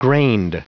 Prononciation du mot grained en anglais (fichier audio)
Prononciation du mot : grained